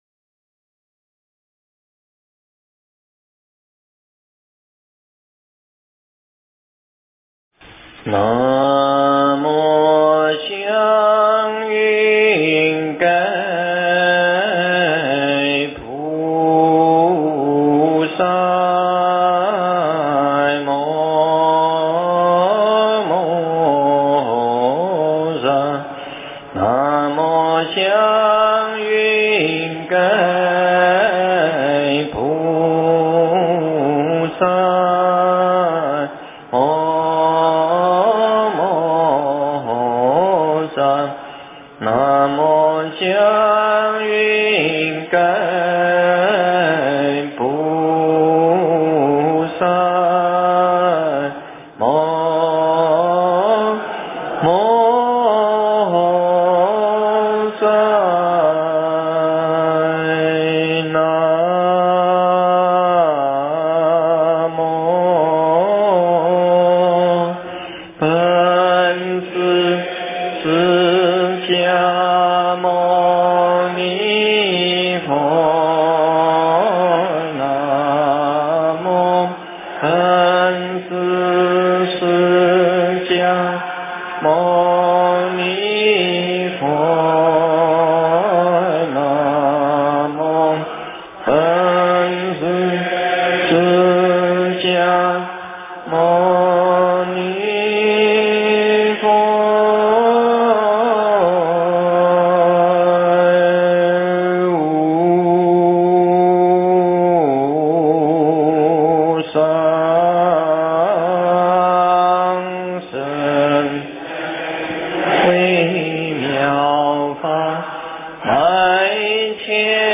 经忏
佛音 经忏 佛教音乐 返回列表 上一篇： 妙法莲华经观世音菩萨普门品--佛光山梵呗团 下一篇： 摩诃般若菠萝蜜--佚名 相关文章 观音与忏悔--网络 观音与忏悔--网络...